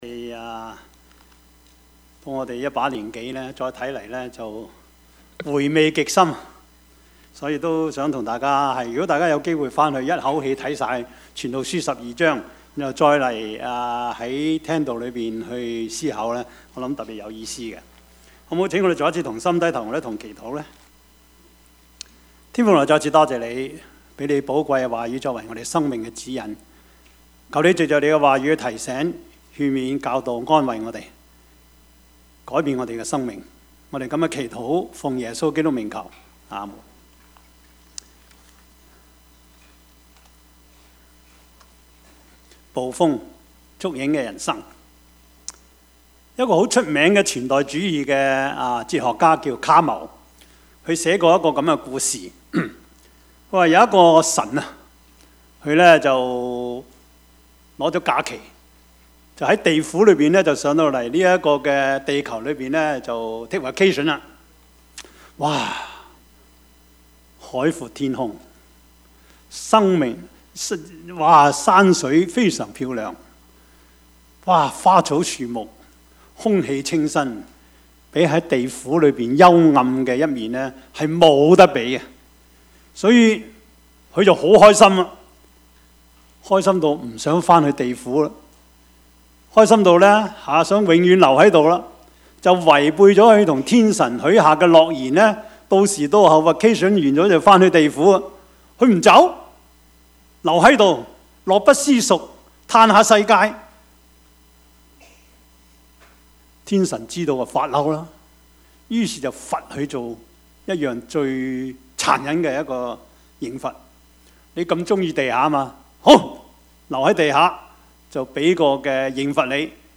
Service Type: 主日崇拜
Topics: 主日證道 « 識時務者為俊傑 你們要聽他 »